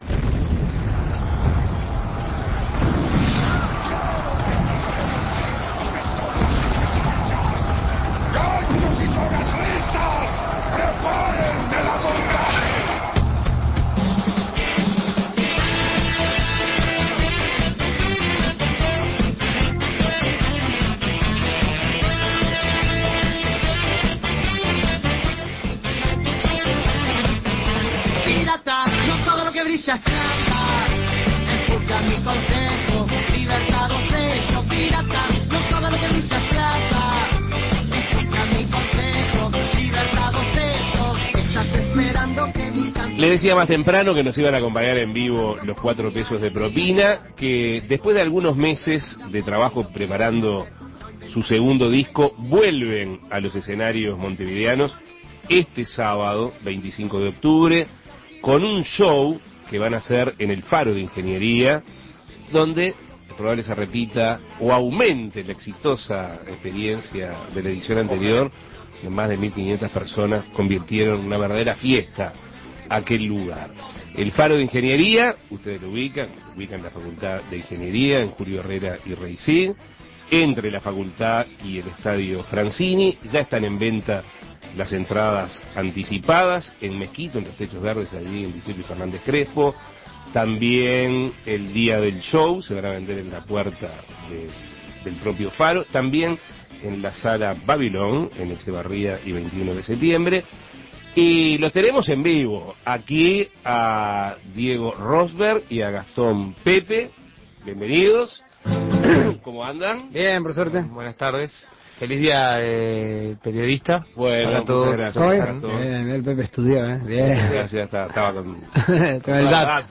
Cuatro pesos de propina volverá el próximo sábado 25 de octubre a los escenarios con un nuevo show a un lado del faro de la Facultad de Ingeniería. Para hablar de este show y del próximo disco que están por grabar, algunos de sus integrantes visitaron Asuntos Pendientes y brindaron un poco de su música.
Entrevistas